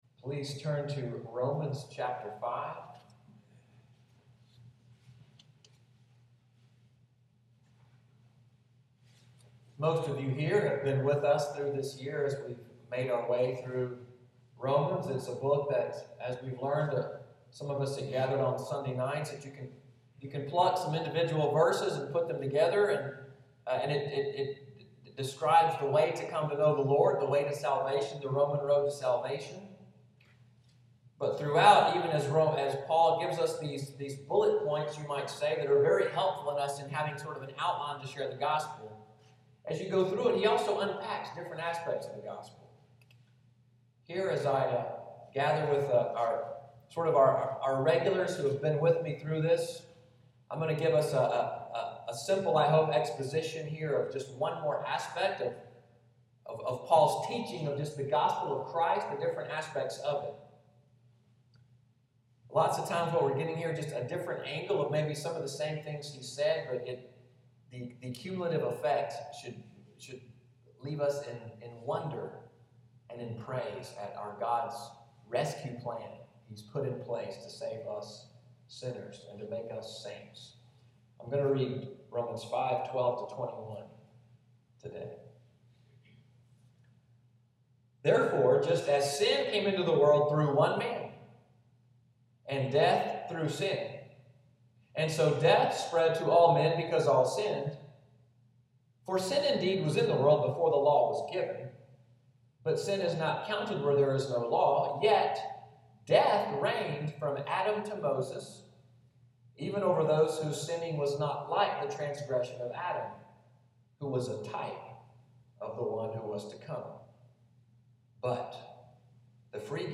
Sunday’s sermon, “The One and the Many,” April 19, 2015